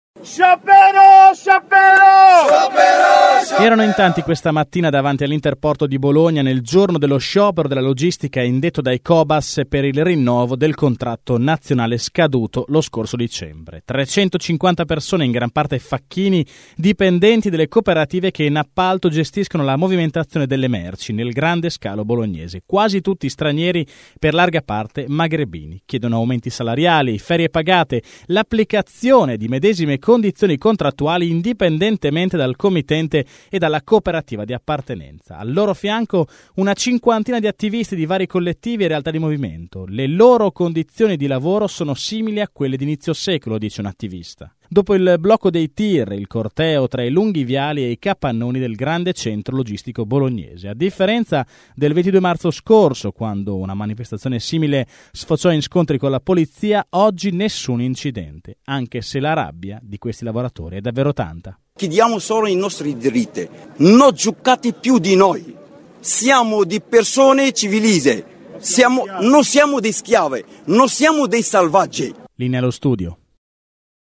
servizio-sciopero-logistica_Bologna.mp3